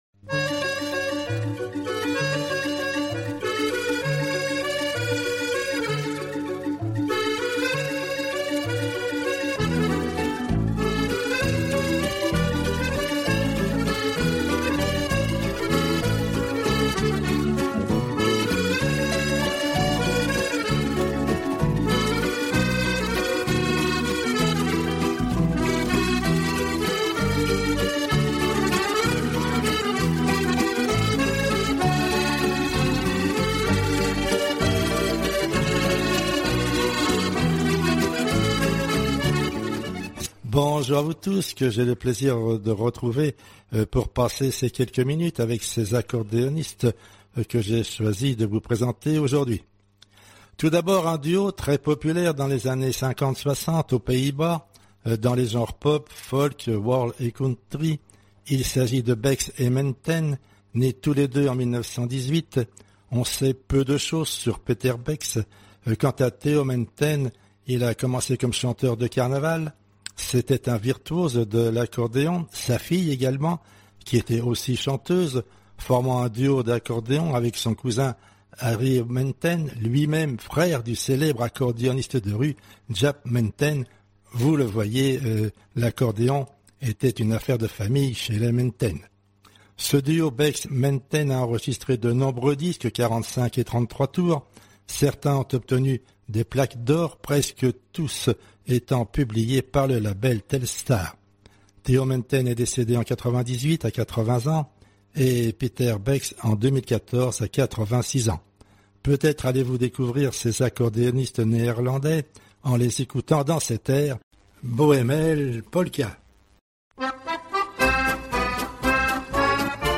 Amateurs d’accordéon bonjour